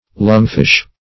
Lungfish \Lung"fish`\, n. (Zool.)